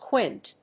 発音記号
• / kwínt(米国英語)